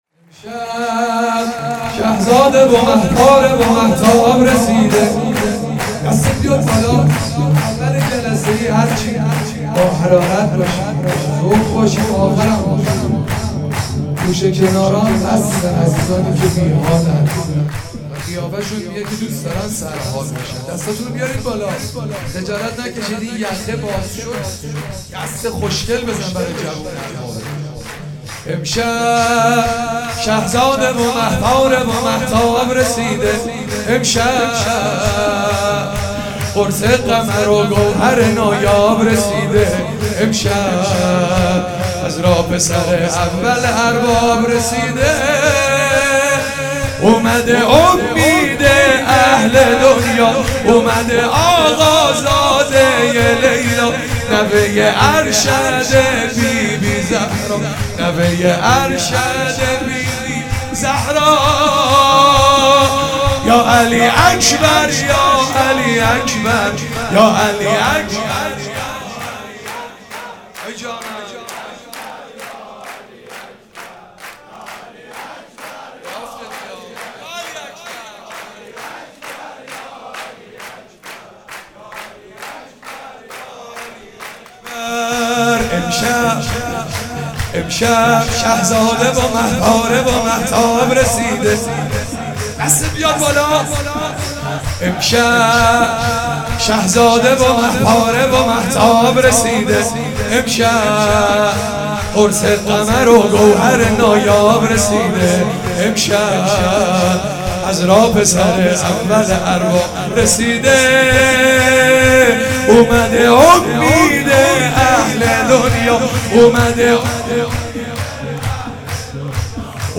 چهاراه شهید شیرودی حسینیه حضرت زینب (سلام الله علیها)
شور- امشب شهزاده و مه پاره و مهتاب رسیده